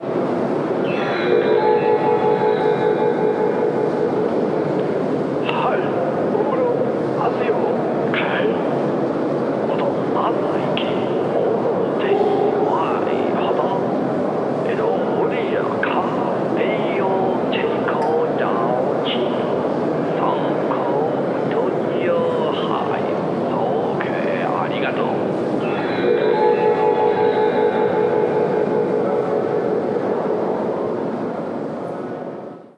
Voz de megafonía en japonés
megafonía
Sonidos: Voz humana
Sonorización. Megafonia